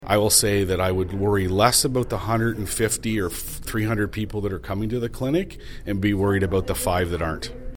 Committee Chair Councillor Paul Carr comments on people’s concerns.